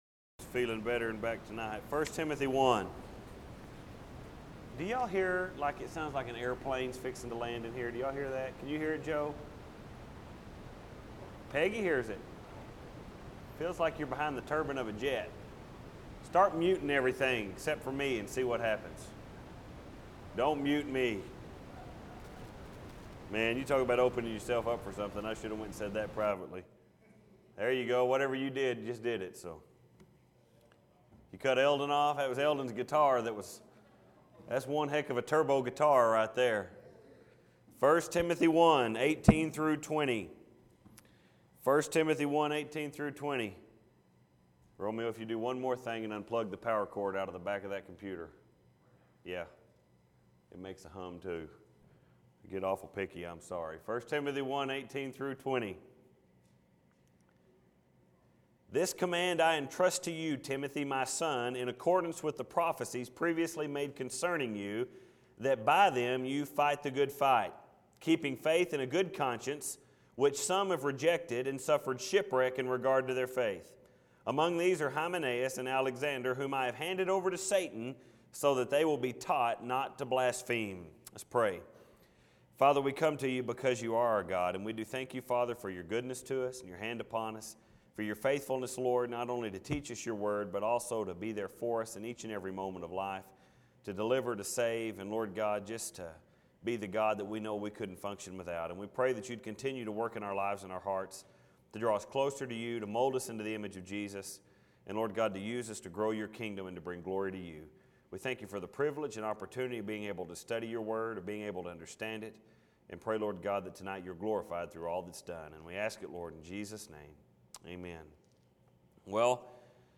Just by reading this text to get started, Or even by reading the title to the sermon, It is obvious that we are talking about an often overlooked concept to the Christian life.